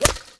rifle_hit_liquid2.wav